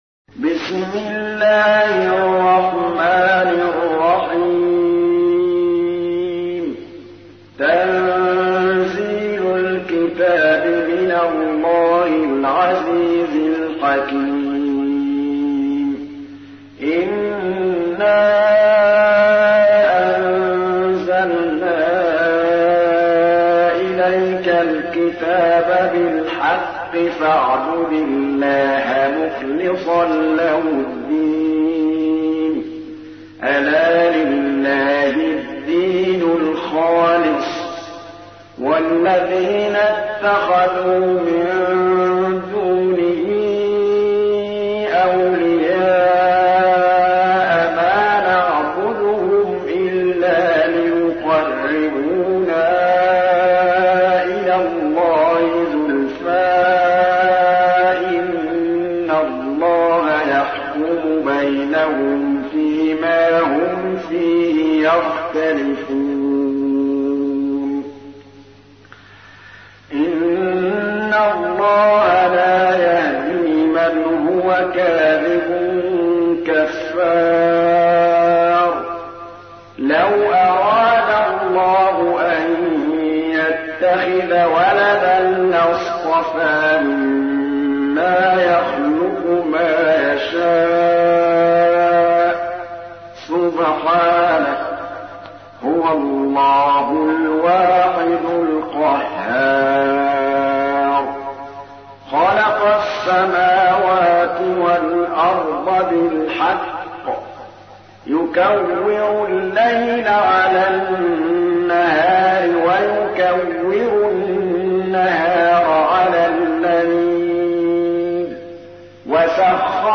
تحميل : 39. سورة الزمر / القارئ محمود الطبلاوي / القرآن الكريم / موقع يا حسين